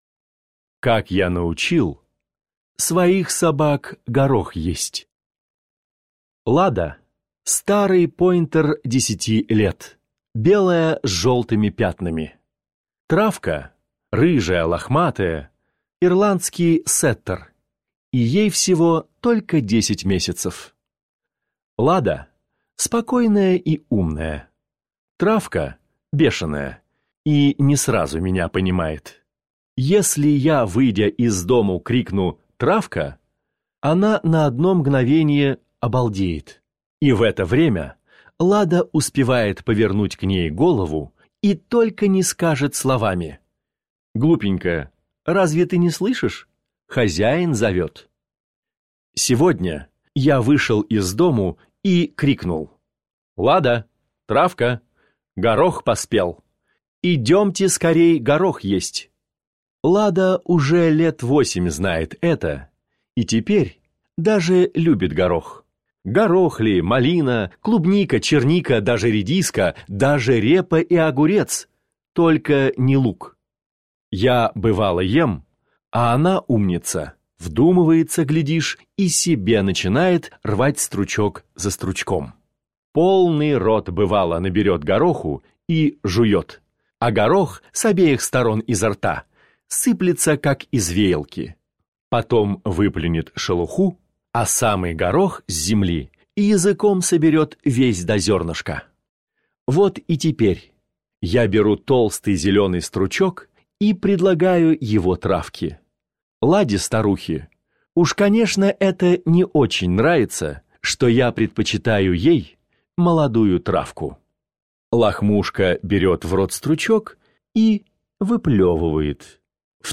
Как я научил своих собак горох есть - аудио рассказ Пришвина - слушать | Мишкины книжки